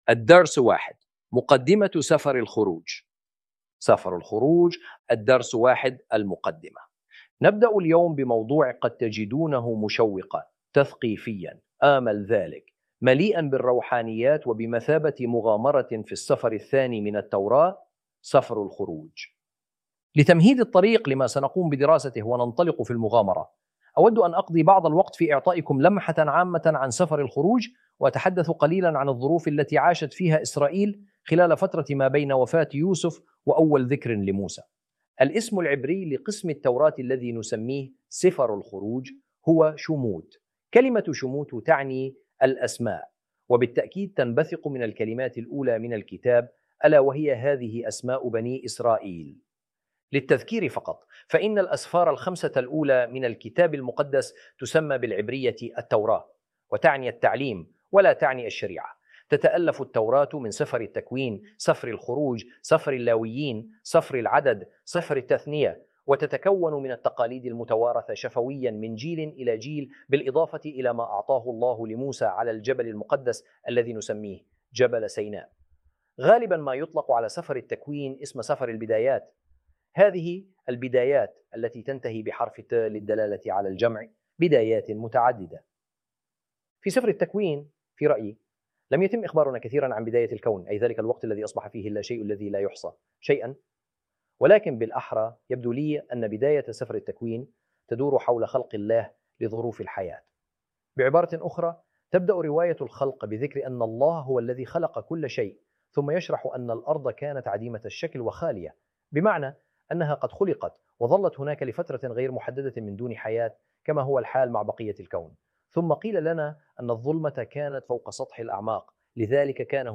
ar-audio-exodus-lesson-1-intro.mp3